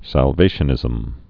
(săl-vāshə-nĭzəm)